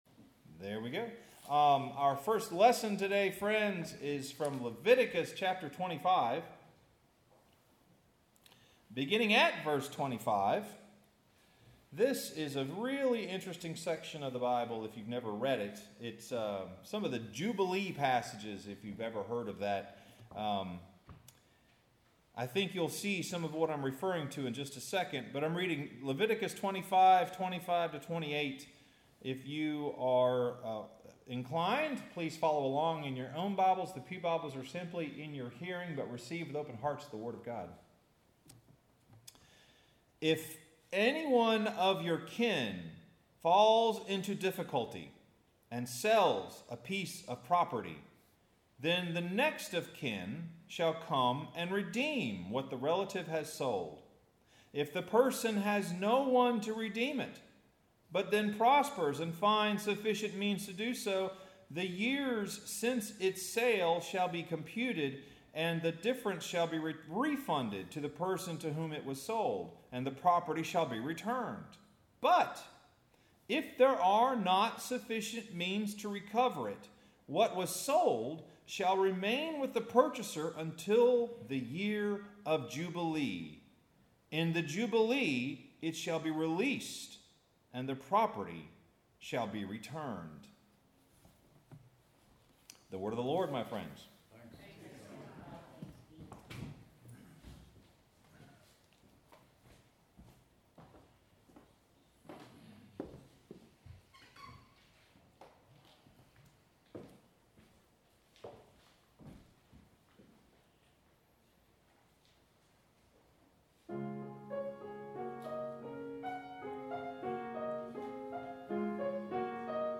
Sermon – Trespassing Debts with Debtors